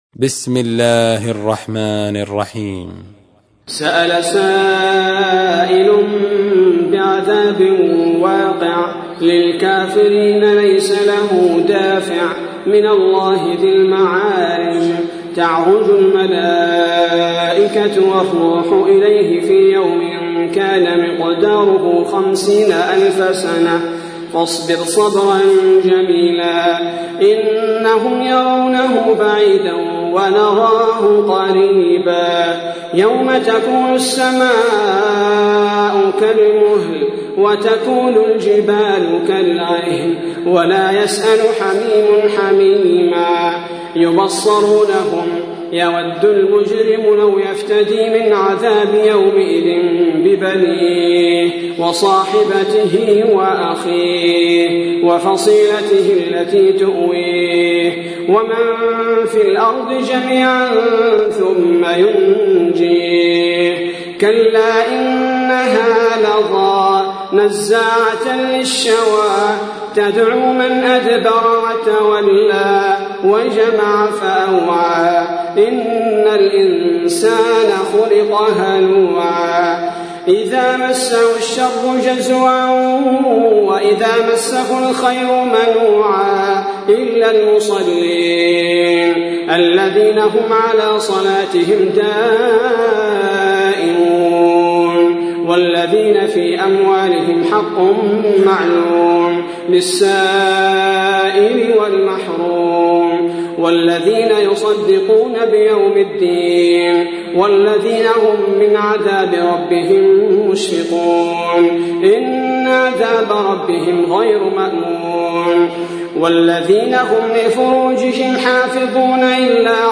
تحميل : 70. سورة المعارج / القارئ عبد البارئ الثبيتي / القرآن الكريم / موقع يا حسين